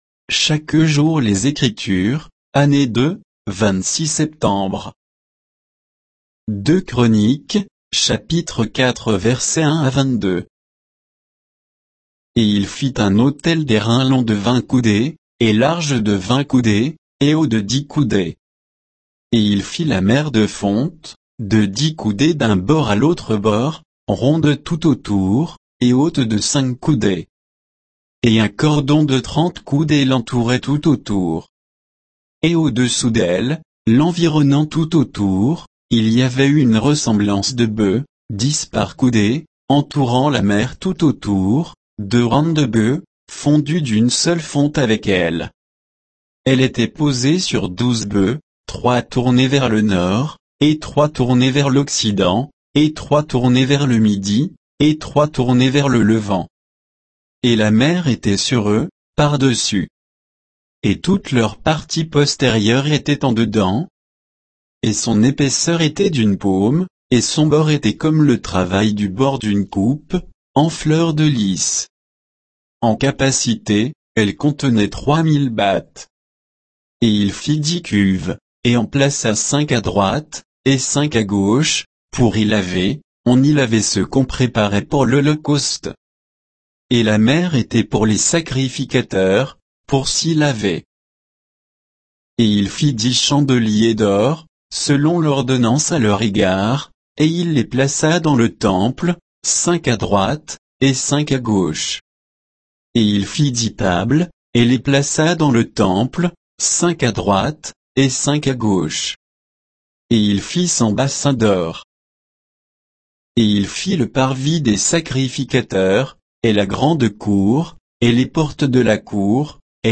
Méditation quoditienne de Chaque jour les Écritures sur 2 Chroniques 4, 1 à 22